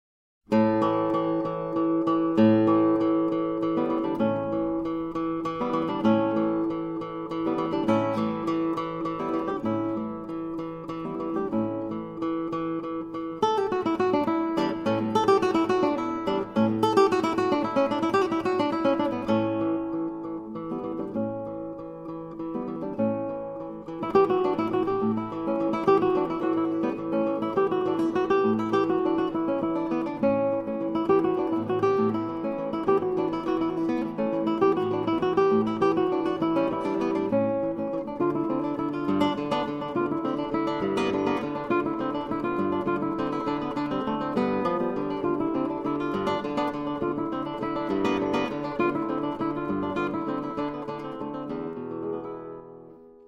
SOLO GUITAR WORKS